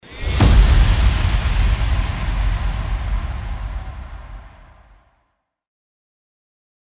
FX-660-IMPACT
FX-660-IMPACT.mp3